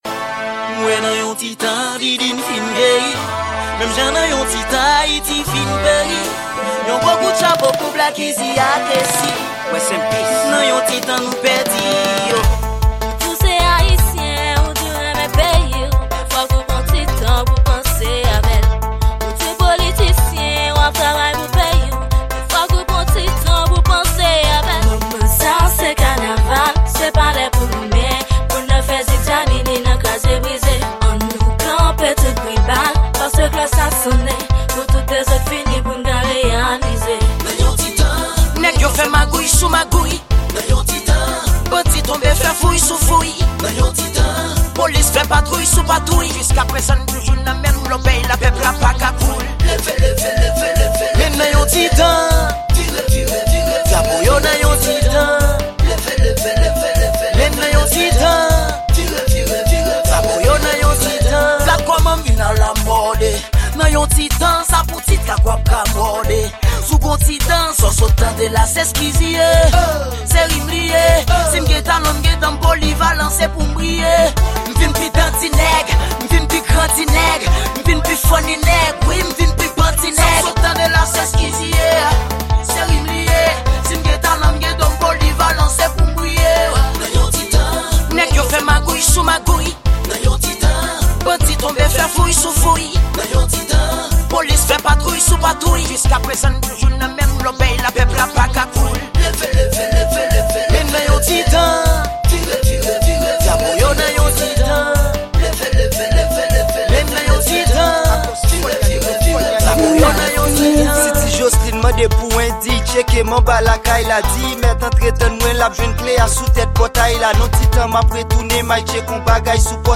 Genre: K-naval.